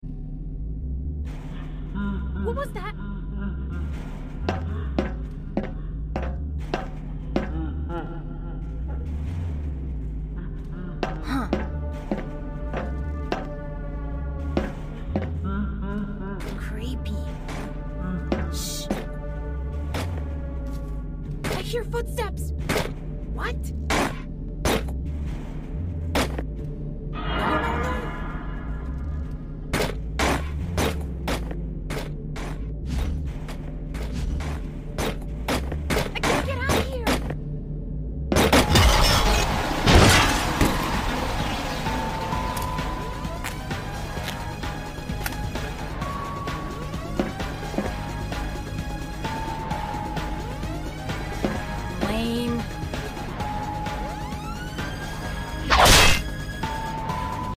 FNAF Movie Animatronics Counter Jumpscares sound effects free download
FNAF Movie Animatronics Counter Jumpscares (Freddy Fazbear & Gregory Voice Lines 🧑🐻)